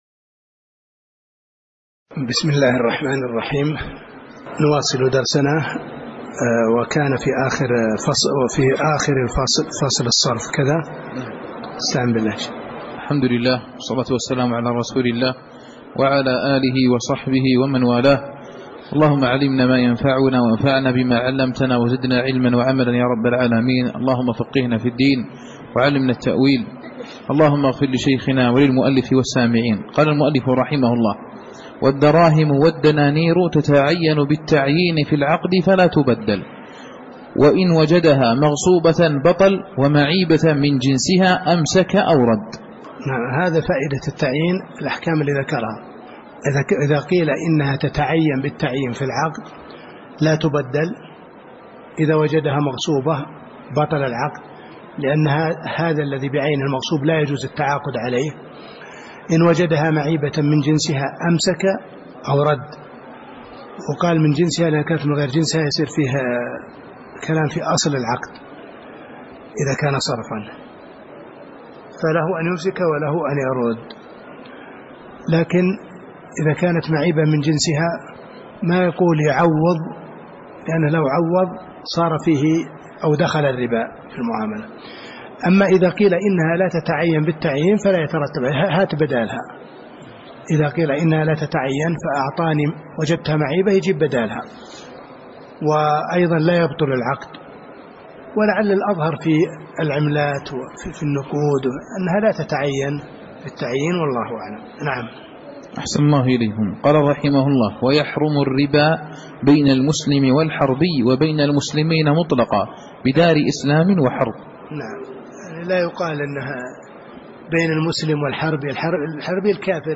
تاريخ النشر ١٢ جمادى الآخرة ١٤٣٨ هـ المكان: المسجد النبوي الشيخ